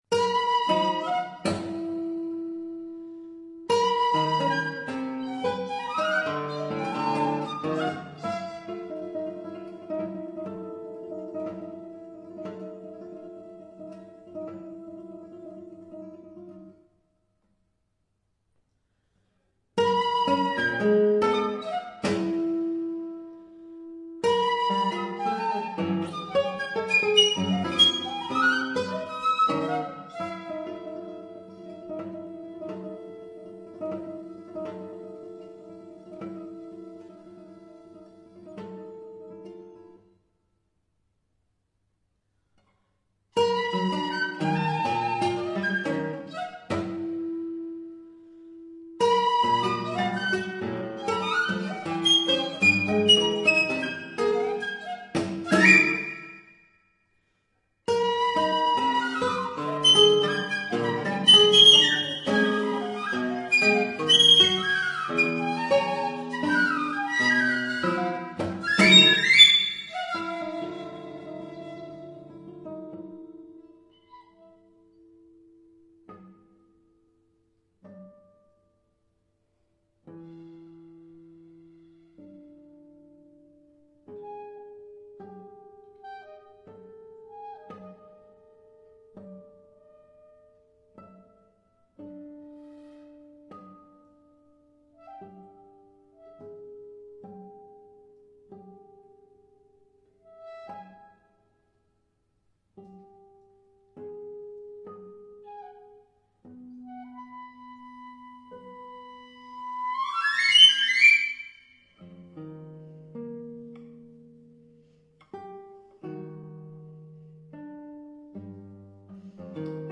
per ottavino e chitarra / for piccolo and guitar (2011)